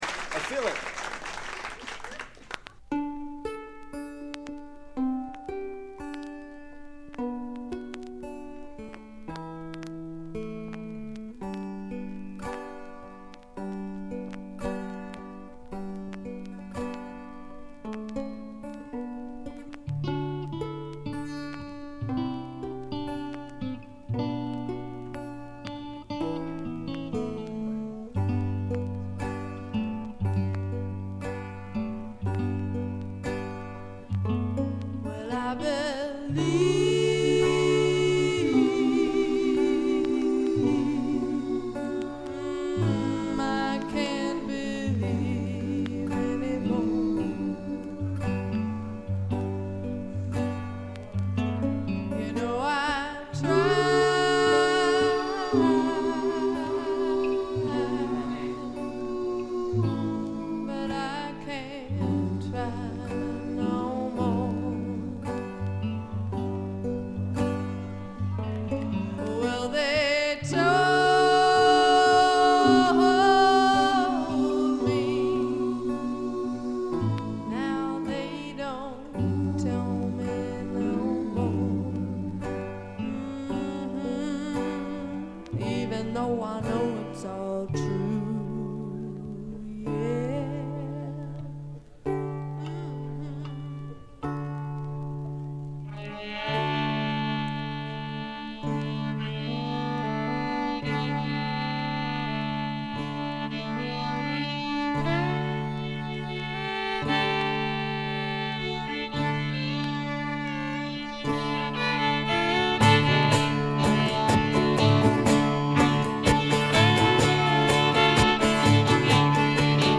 バックグラウンドノイズ、チリプチ多め大きめ。
カナダを代表するヒッピー・フォークの3人組で大学でのライヴ録音、自主制作だと思います。
ギター、ヴァイオリン、ダルシマー、フルート等によるアコースティックなフォークですが、とてもへんてこな感覚がつき纏います。
試聴曲は現品からの取り込み音源です。